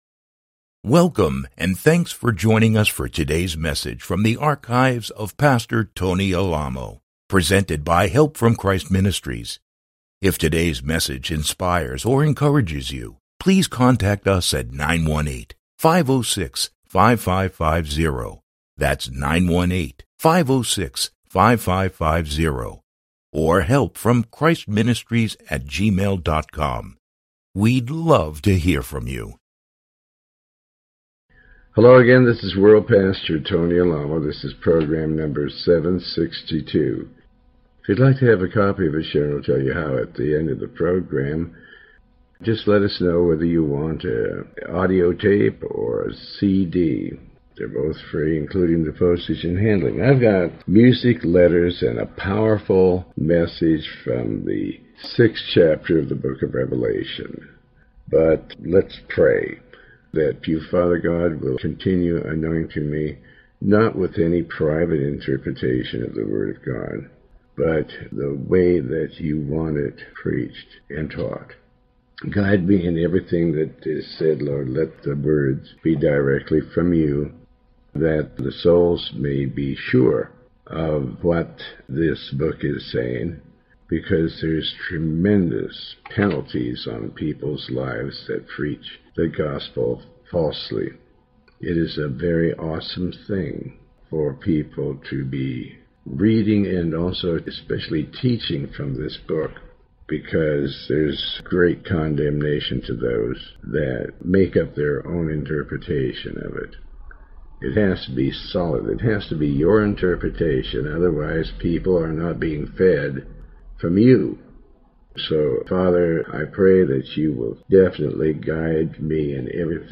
Pastor Alamo reads and comments on the Book of Revelation chapter 6. This program is part of a series covering the entire Book of Revelation